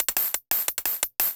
Index of /musicradar/ultimate-hihat-samples/175bpm
UHH_ElectroHatC_175-02.wav